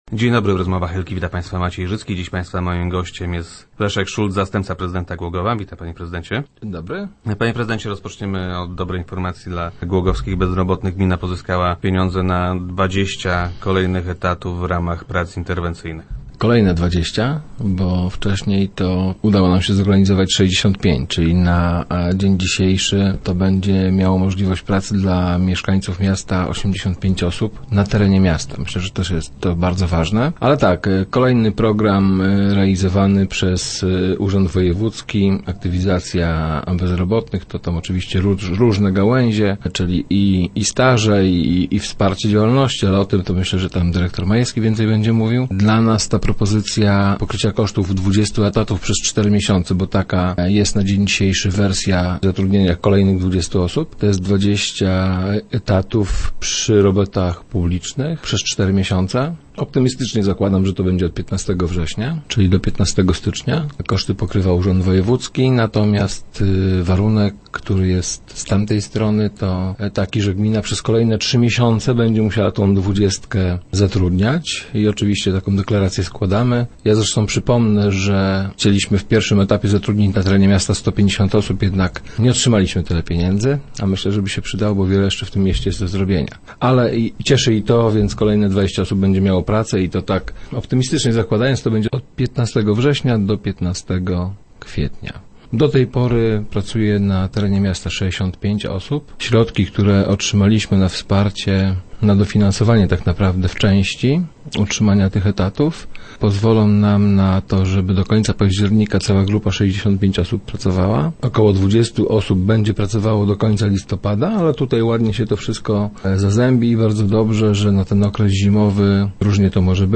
Optymistycznie zakładam, że zatrudnione osoby rozpoczną pracę od 15 września. Urząd wojewódzki pokryje koszty ich zatrudnienia do 15 stycznia. My natomiast będziemy zatrudniali tę dwudziestkę jeszcze przez trzy kolejne miesiące - mówi wiceprezydent Szulc, który był gościem Rozmów Elki.